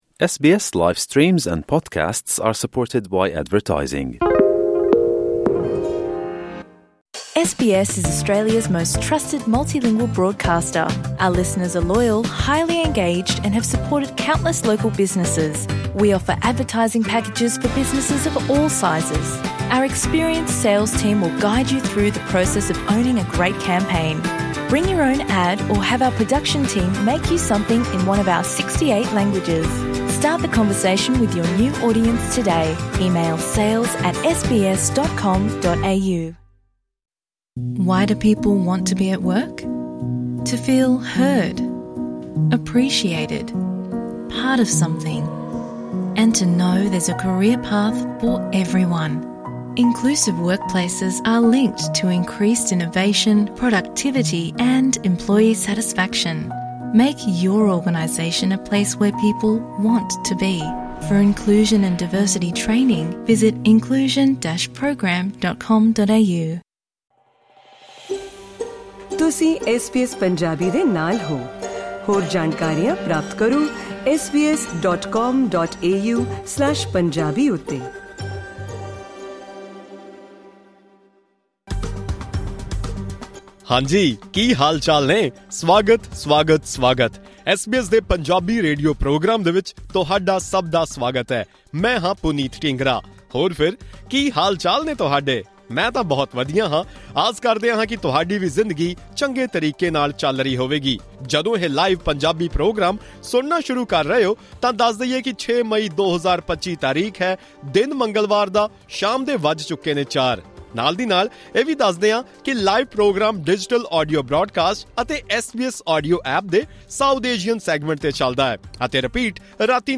Today's radio program presented a report about the story of Ali France, who defeated Peter Dutton, who had been winning the 'Dixon' seat continuously for 24 years. Additionally, the show discussed why and how the time taken to build houses in Australia is increasing.